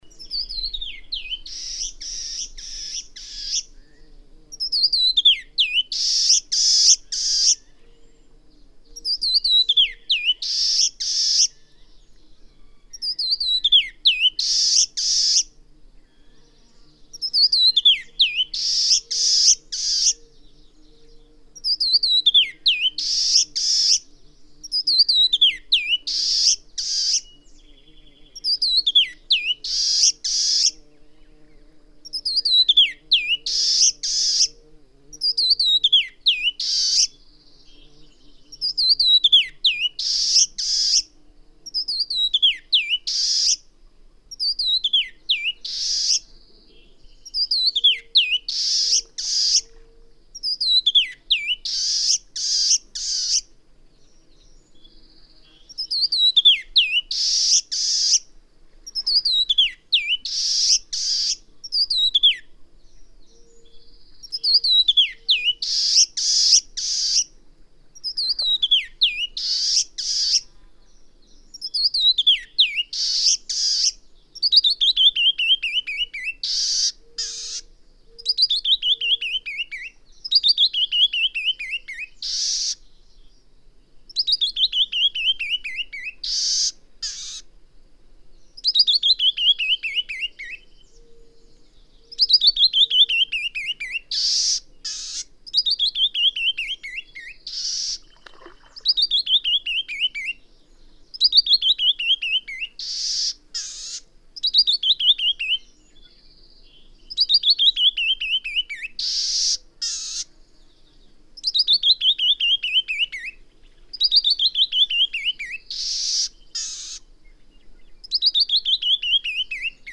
Ritardando—canyon wren
♫642. Rapid singing in response to an "intruding songster." Full confession: I used playback of a canyon wren song to stimulate a reluctant singer, only to get this tirade in response.
Oxbow, Hell's Canyon, Oregon.
642_Canyon_wren.mp3